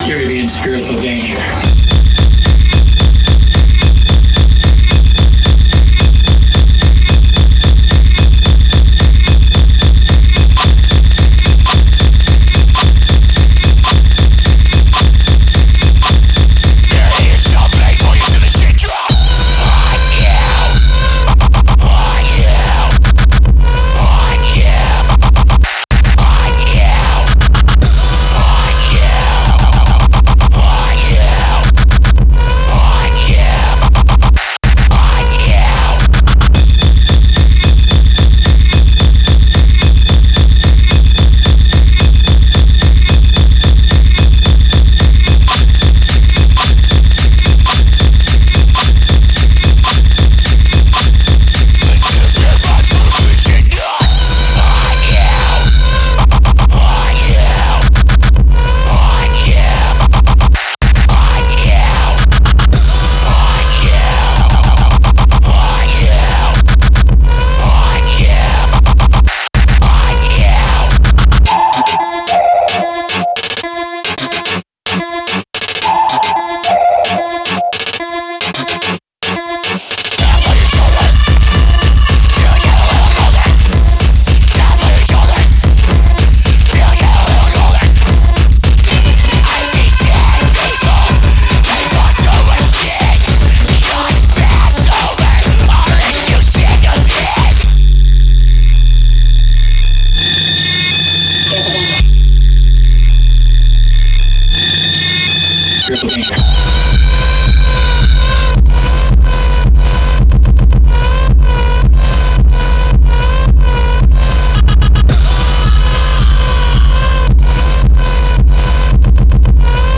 noise, gabber, digital hardcore, breakcore, ,